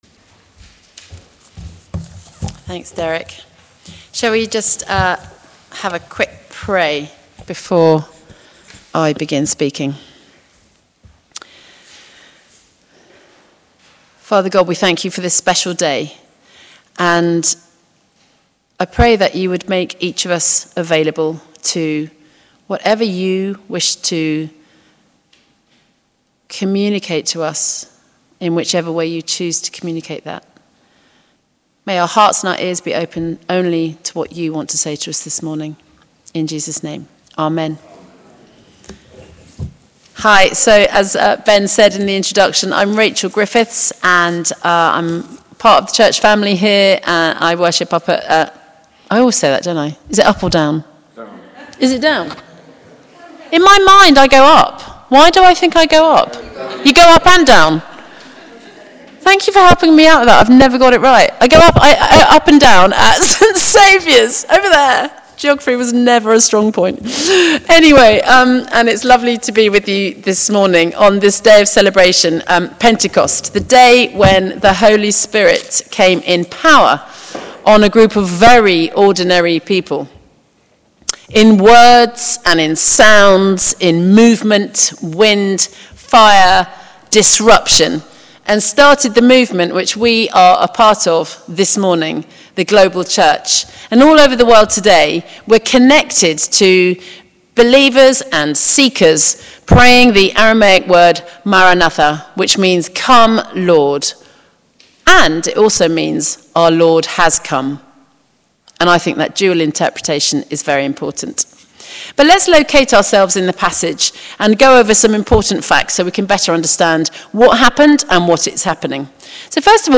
Listen to our 9.30am and 11.15am sermon here:
Passage: Acts 2:1-21 Service Type: Morning Worship